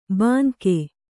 ♪ bānke